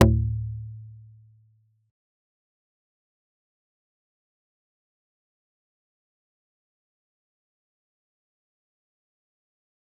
G_Kalimba-D2-pp.wav